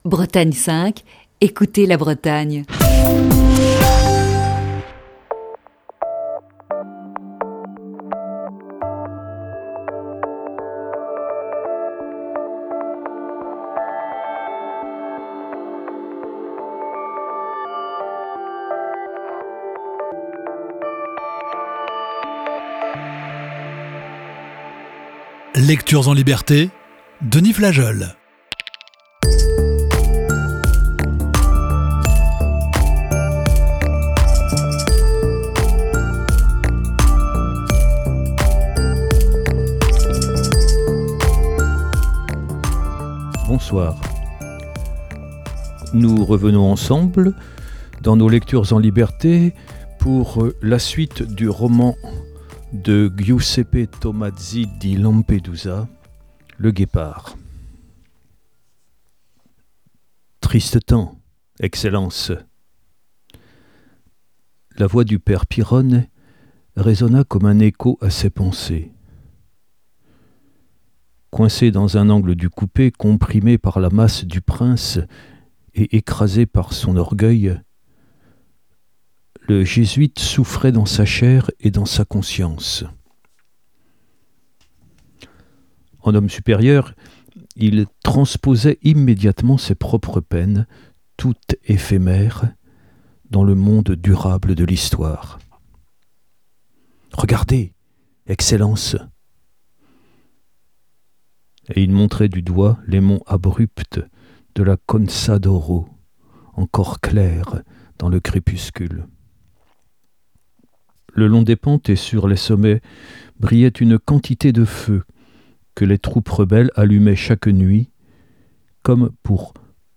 Voici ce soir la troisième partie de ce récit.